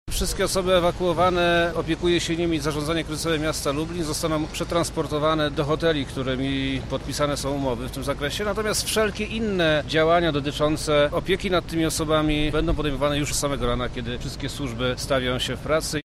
Przemysław Czarnek – mówi Wojewoda Lubelski Przemysław Czarnek.